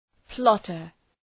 Προφορά
{‘plɒtər}